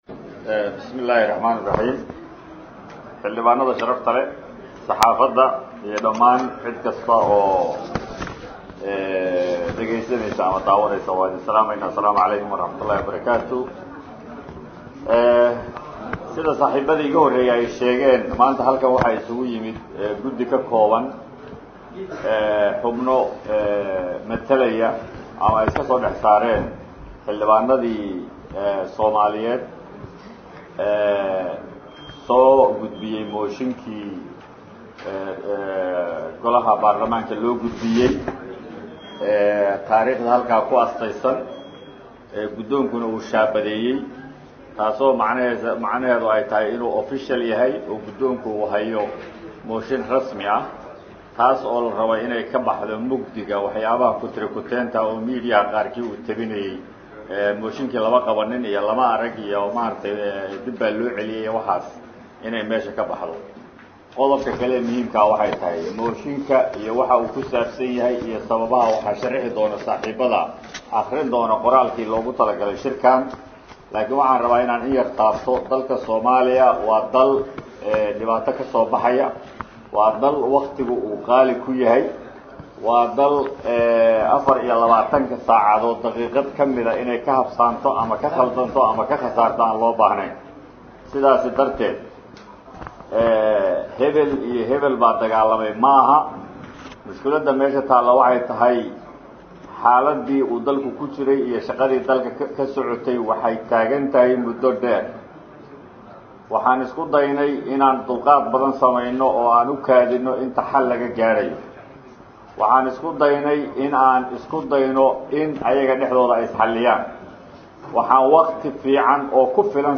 Codadka_Xildhibaannadii_kulanka_yeeshay.mp2